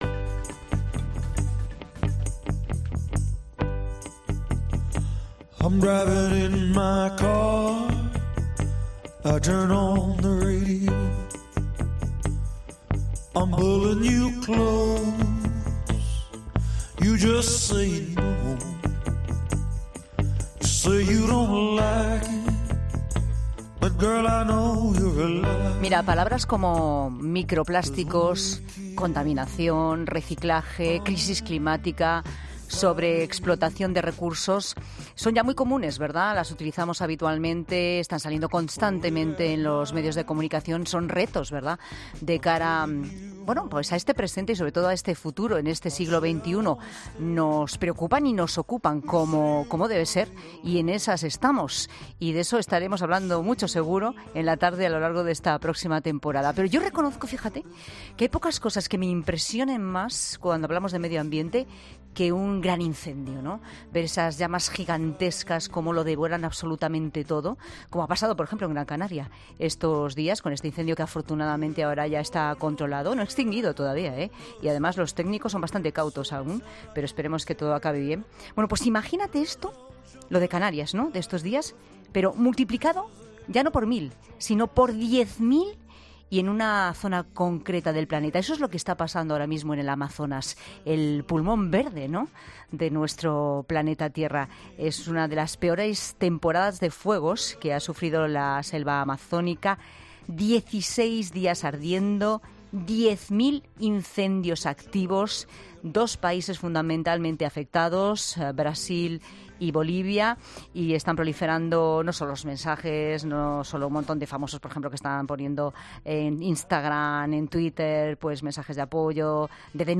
El obispo español en la Amazonia desde hace 26 años, Adolfo Zon, cuenta en 'La Tarde de COPE' cómo se vive en Brasil la situación